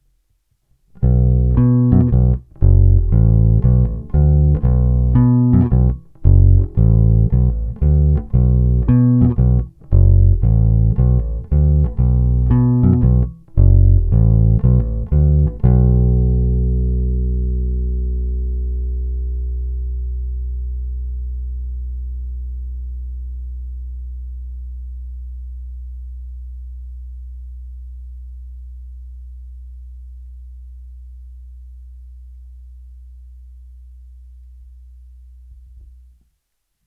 Zvuk je poctivý Precision, ten z nejpoctivějších.
Není-li uvedeno jinak, jsou provedeny rovnou do zvukové karty a jen normalizovány, s plně otevřenou tónovou clonou.
Hra u krku